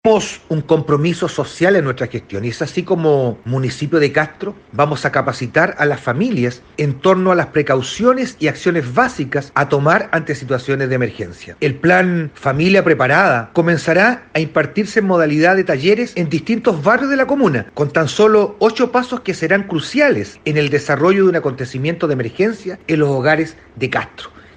cuña-alcalde-familia-preparada-2.mp3